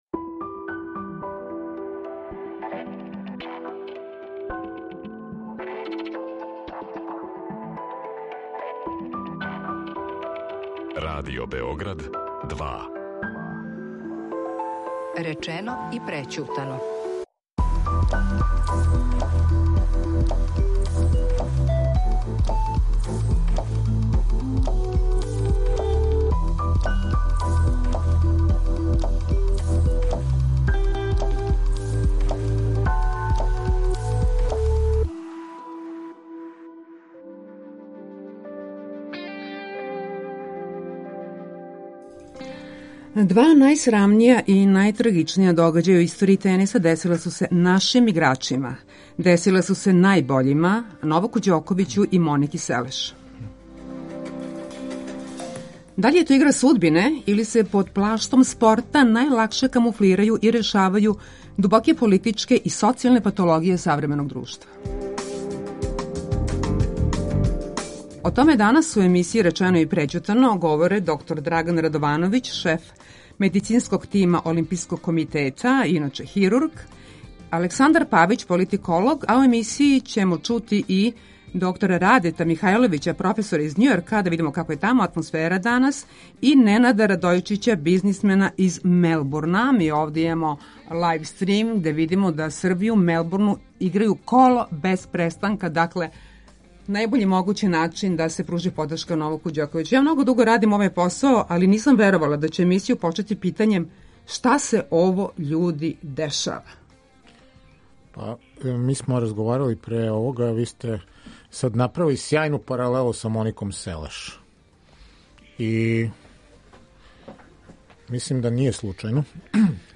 Гости у студију